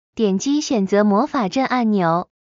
点击选择魔法阵按钮.MP3